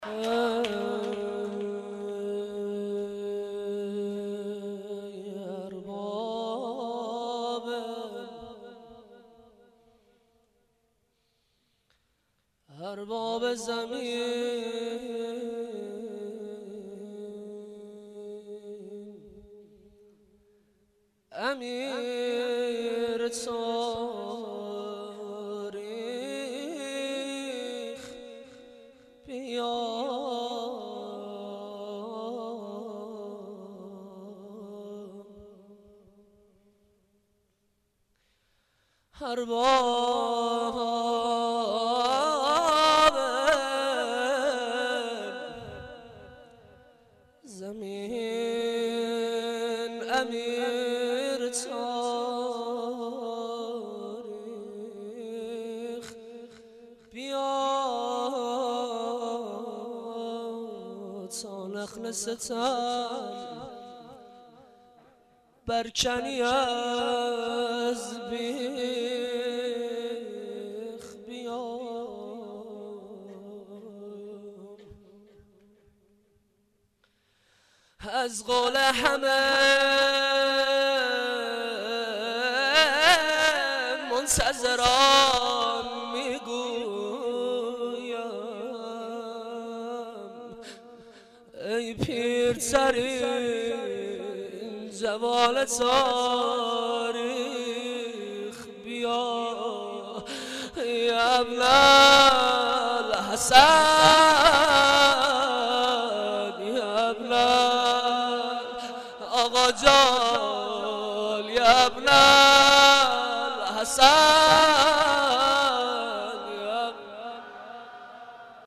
مناجات.mp3